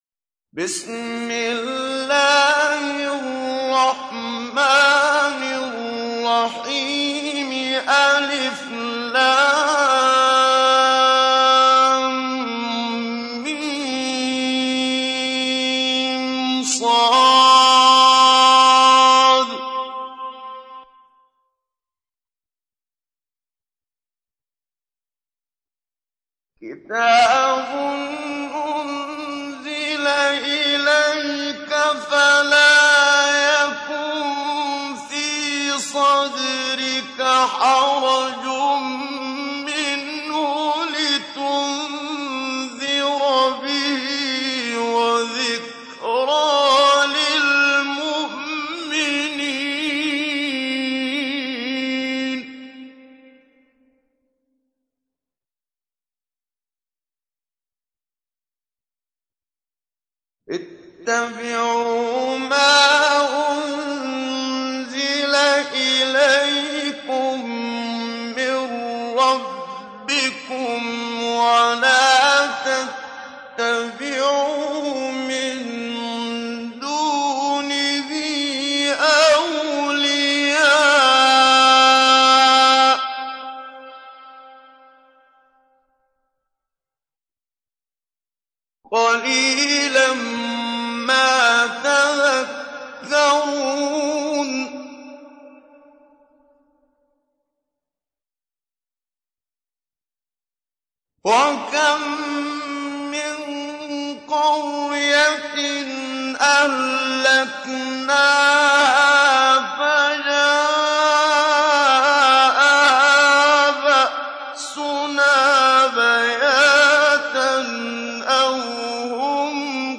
تحميل : 7. سورة الأعراف / القارئ محمد صديق المنشاوي / القرآن الكريم / موقع يا حسين